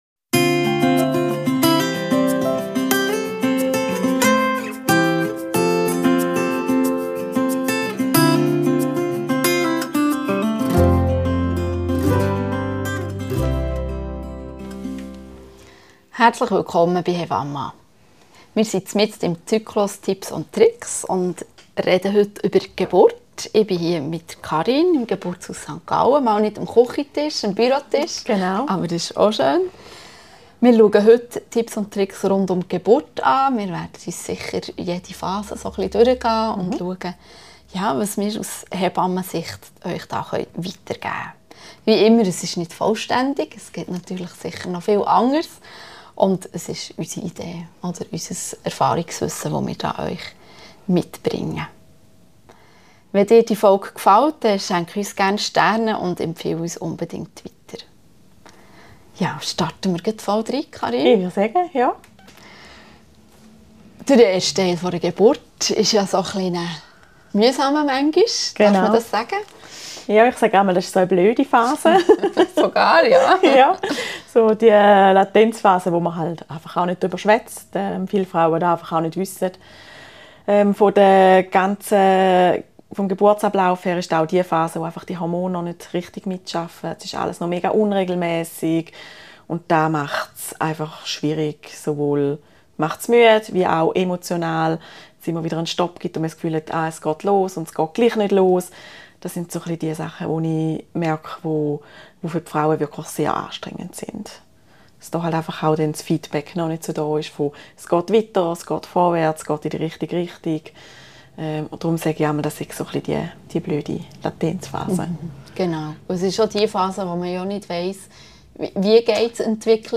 im Geburtshaus in St. Gallen